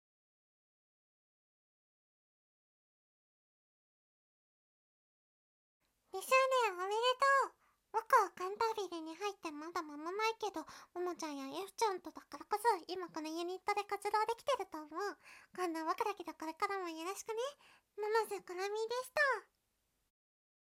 セリフ